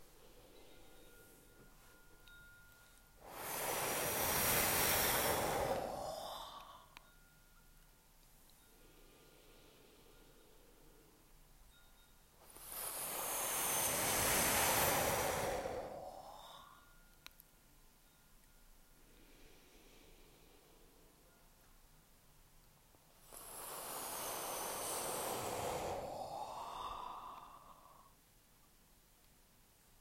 Fifth Sound: FU
Similarity: like gently saying “foo”